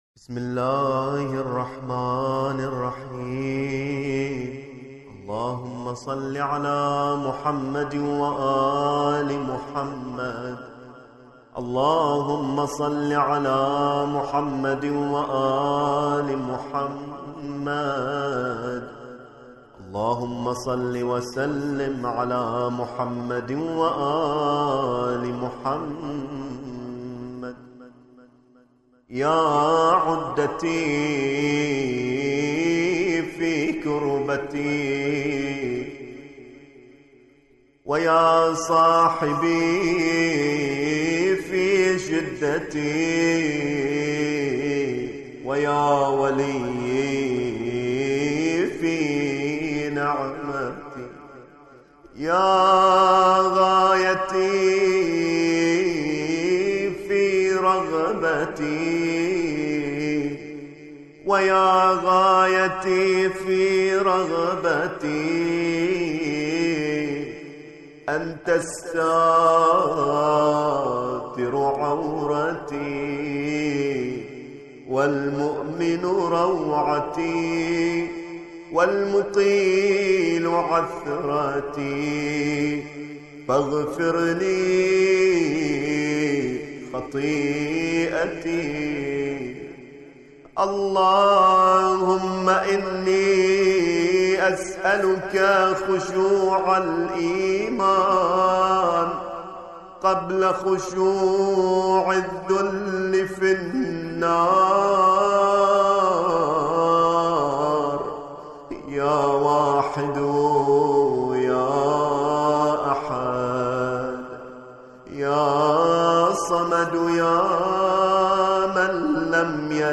ملف صوتی دعاء ياعدتي